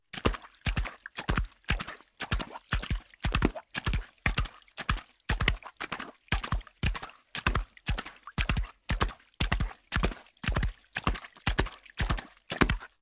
horse_gallop_puddle.ogg